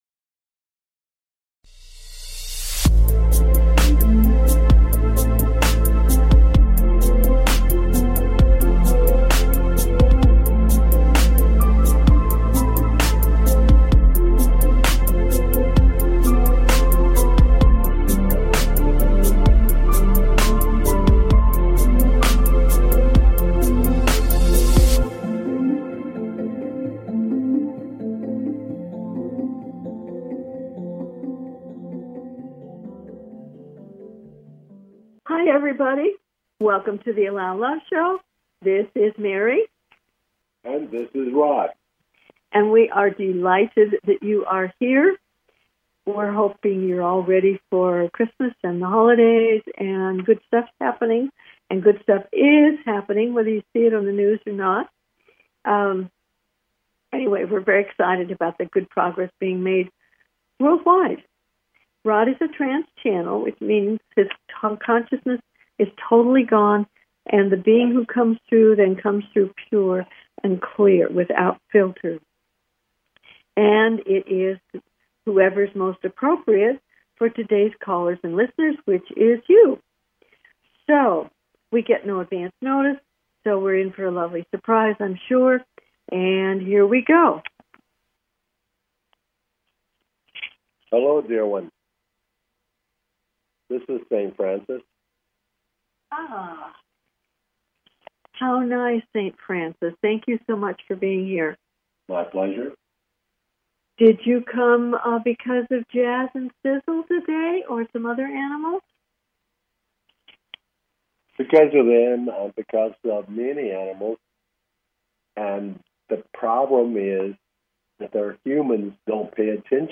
Talk Show Episode
Their purpose is to provide answers to callers’ questions and to facilitate advice as callers request.